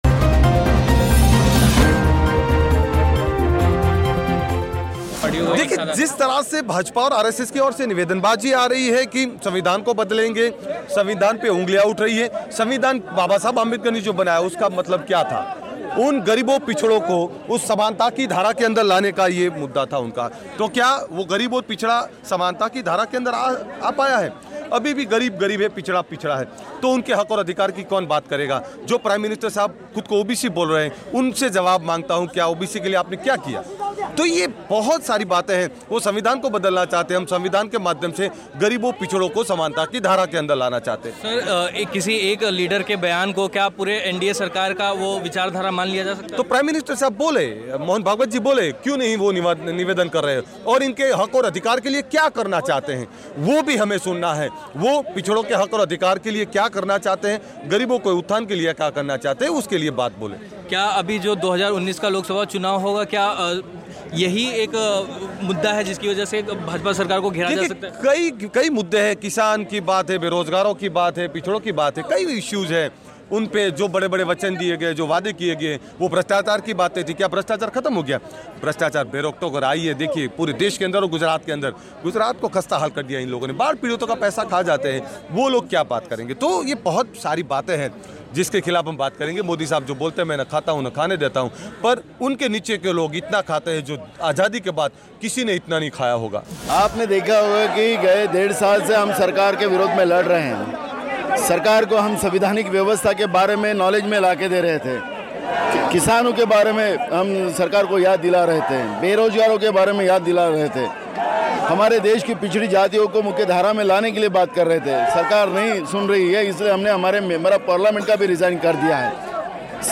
गणतंत्र दिवस के मौके पर मुंबई में कांग्रेस, एनसीपी समाजवादी पार्टी, जम्मू-कश्मीर नेशनल कांफ्रेंस, कई दलों ने संविधान बचाओ रैली निकाली. रैली में शामिल नेताओं ने पिछले दिनों हुई घटनाओं, खासकर केन्द्रीय मंत्री अनंत कुमार हेगड़े के संविधान बदलने को लेकर दिए गए बयान के खिलाफ मोर्चा खोलते हुए संविधान को बचाने की जरूरत बताई.